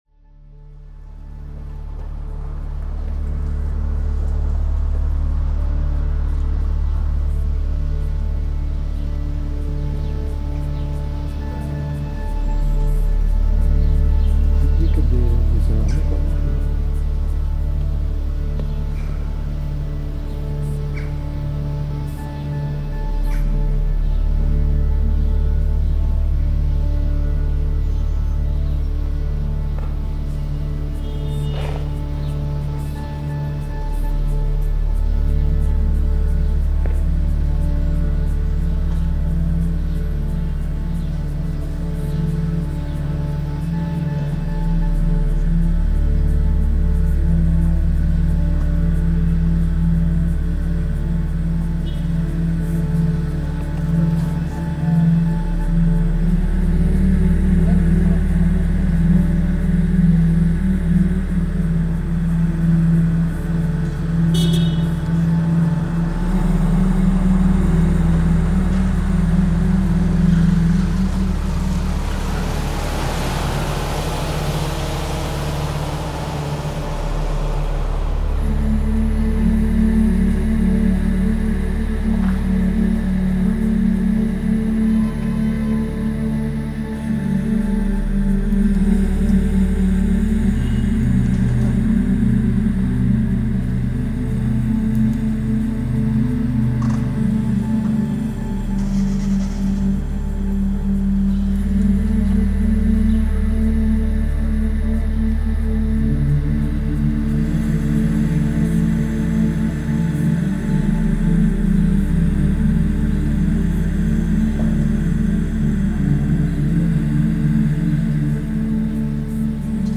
Jerusalem apartheid wall recording